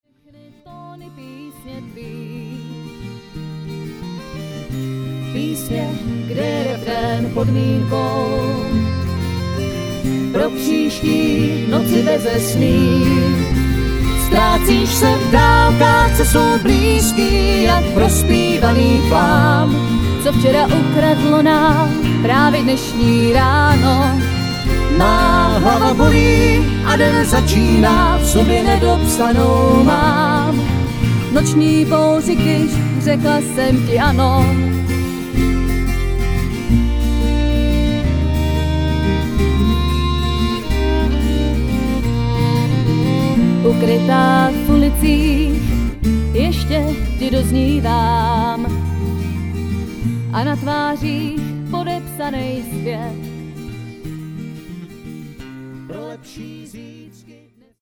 česká folková kapela z Pelhřimova.